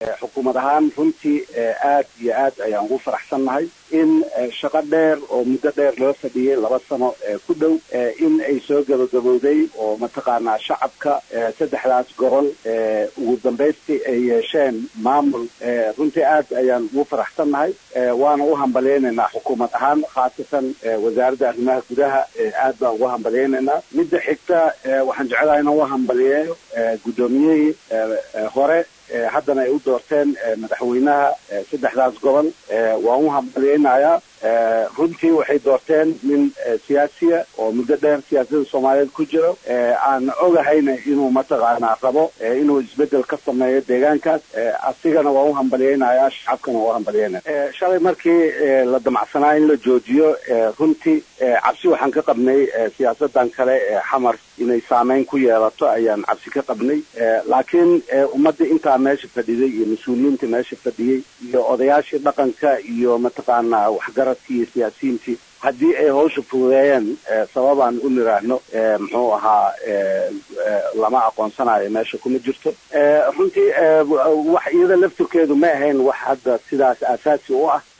“Xukuumad ahaan aad iyo aad ugu faraxsanahay una soo dhoweyneynaa in shaqo muddo dheer loo fadhiyay inay soo gaba-gabowdo oo shacabka ku nool saddexda gobol ay ugu dambeyn yeesheen maamul,” ayuu yiri Goodax ku sheegay wareysi uu maanta VOA-da siiyay.
DHAGEYSO: Codka Wasiirka Arrimaha Gudaha Soomaaliya
DHAGEYSO_Cabdullaahi_Goodax_Barre_HOL.mp2